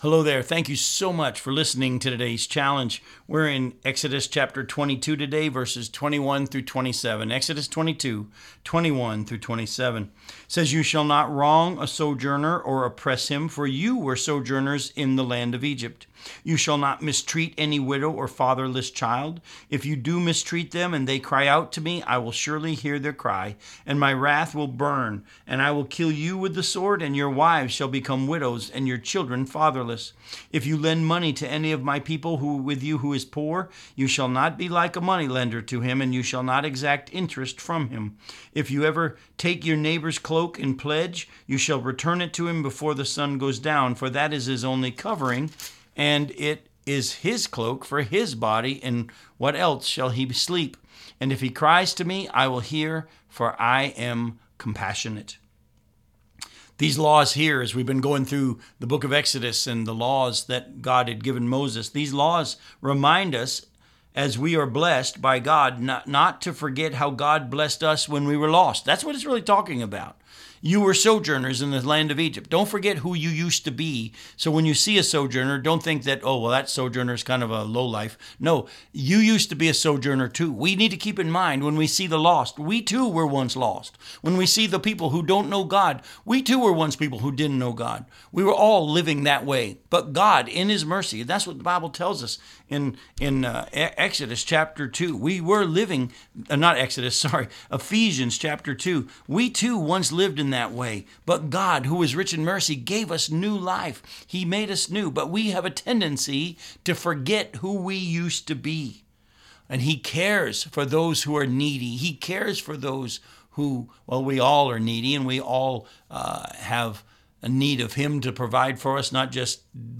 radio program aired on WCIF 106.3 FM in Melbourne, Florida